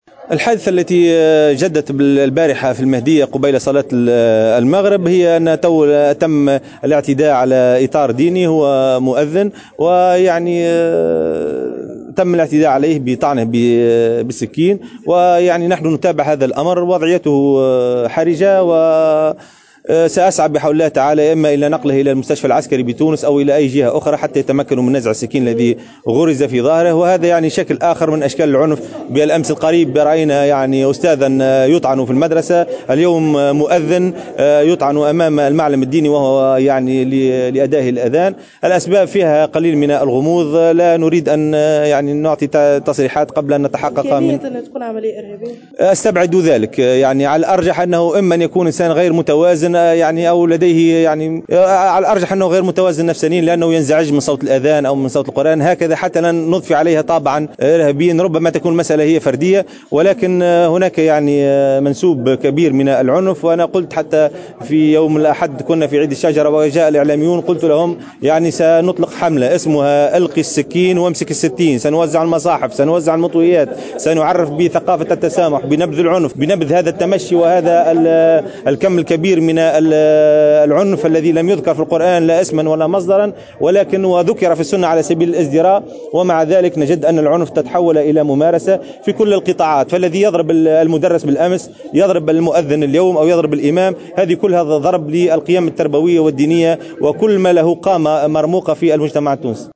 أكد وزير الشؤون الدينية ابراهيم الشائبي في تصريح لـ "الجوهرة أف أم" أنه تم الاعتداء البارحة قبيل صلاة المغرب على مؤذّن طعنا بالسكين بأحد مساجد المهديّة